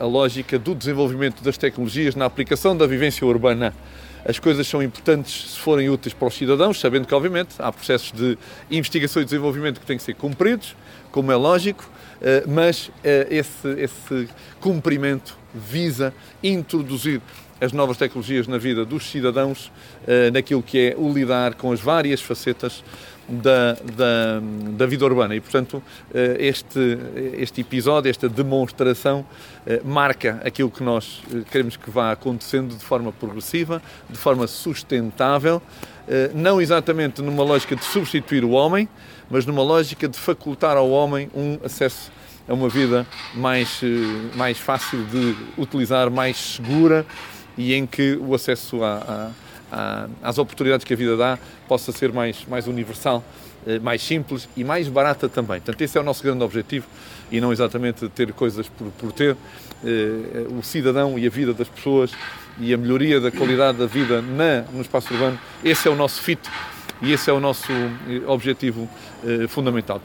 presidenteCMA_smartcities.mp3